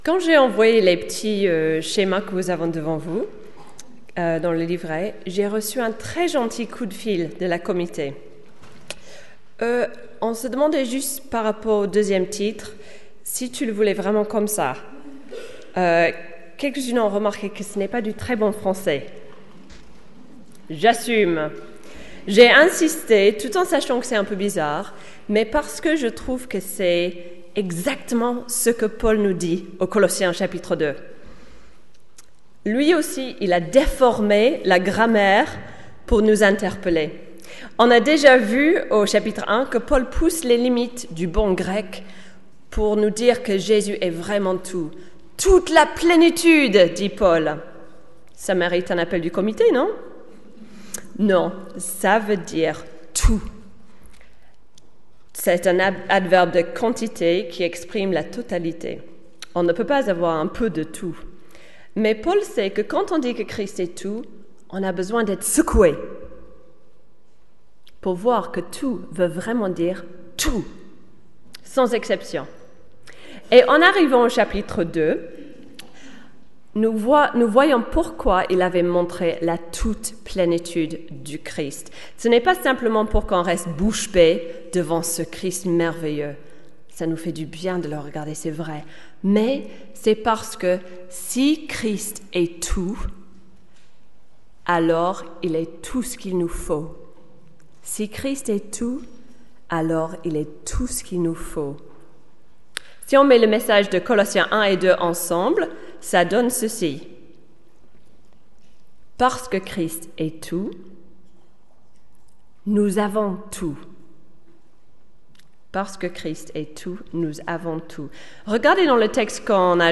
Chrétiennes Consacrées a été une journée pour les femmes organisées par les femmes de notre association. Une journée d’enseignement biblique sur le thème : ‘Christ ; notre plus grand et plus glorieux trésor’.